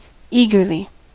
Ääntäminen
Synonyymit heartily with a will zealously Ääntäminen US Haettu sana löytyi näillä lähdekielillä: englanti Käännöksiä ei löytynyt valitulle kohdekielelle.